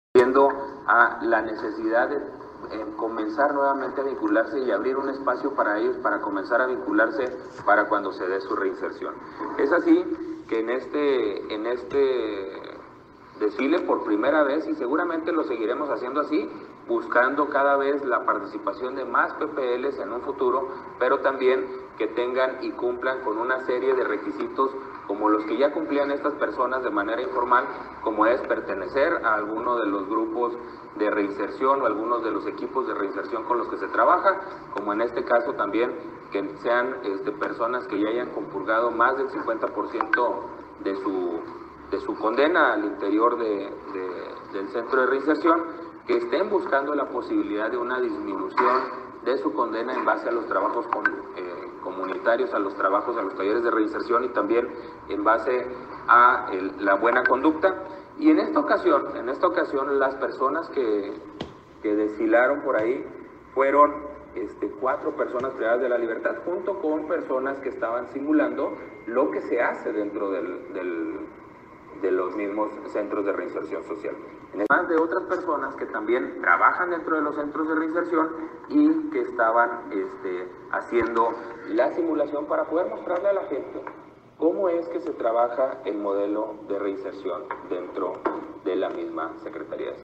AUDIO: GILBERTO LOYA CHÁVEZ, TITULAR DE LA SECRETARÍA DE SEGURIDAD PÚBLICA DEL ESTADO (SSPE)